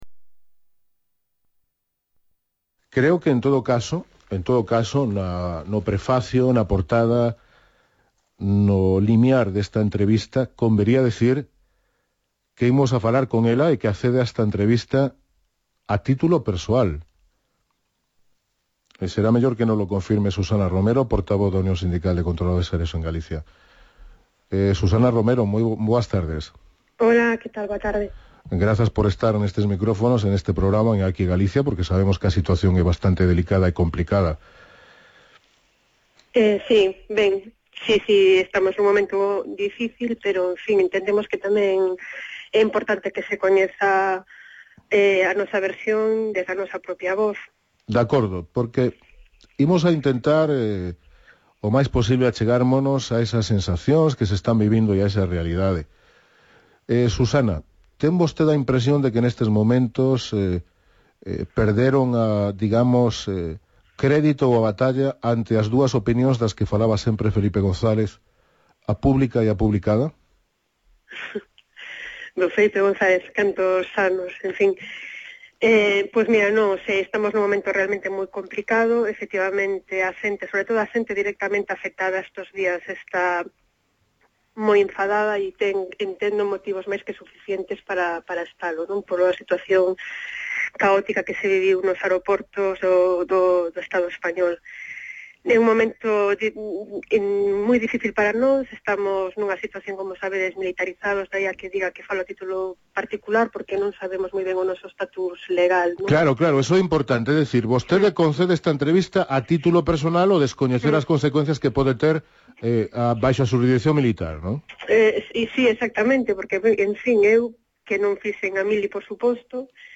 A entrevista na web da Radio Galega, e polo seu evidente interese facilitamos tamén descarga neste enlace.